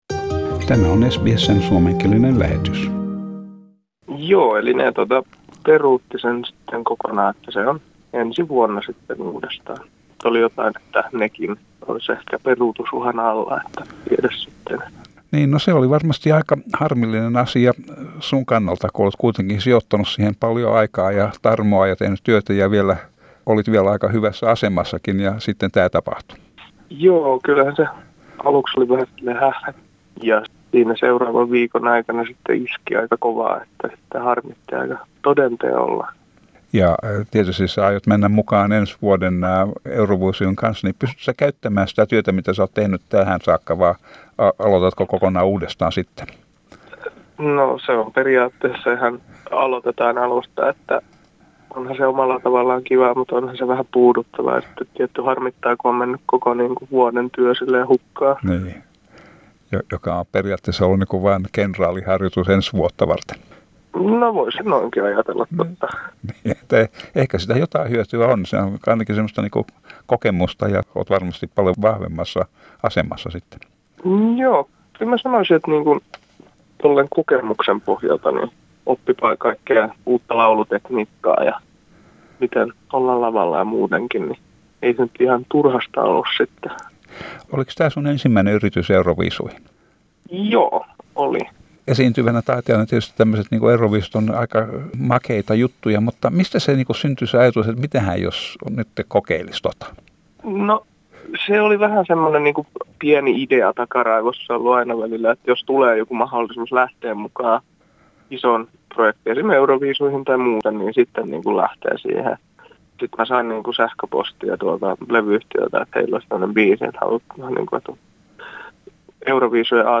Sain hänet langan päähän ja juttelimme Euroviisuista ja siitä mitä nyt taphtuu...